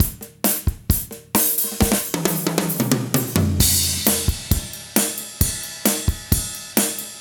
14 rhdrm133roll.wav